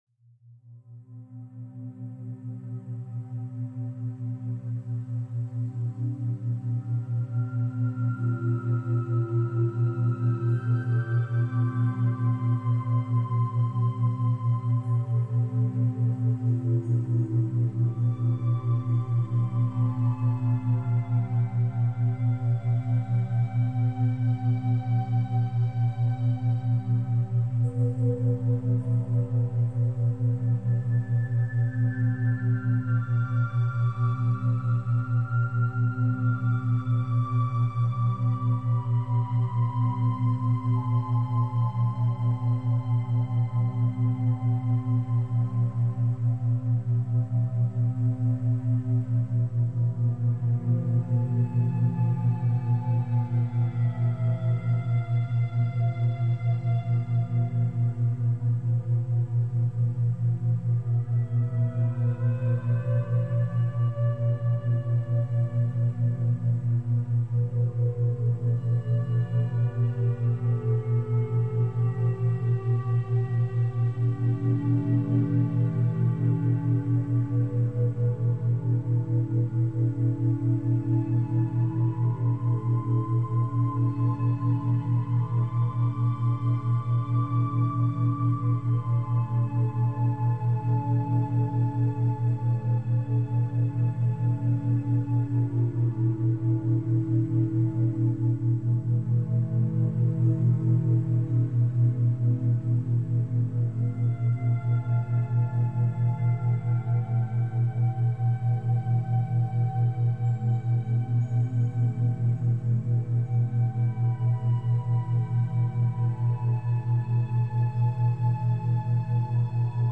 Background Sounds, Programming Soundscapes